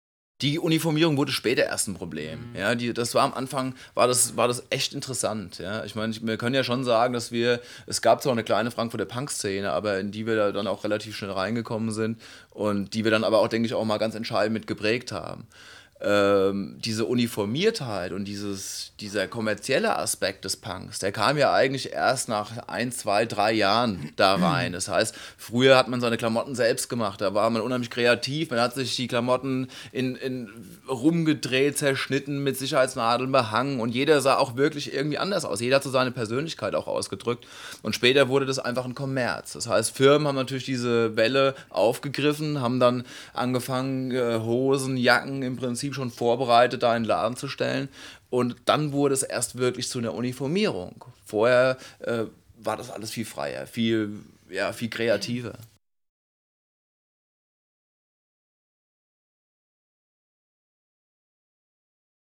Anbei hört hier ein paar Worte der Band zum Release des Albums, direkt aus dem Presse-Kit zur Veröffentlichung.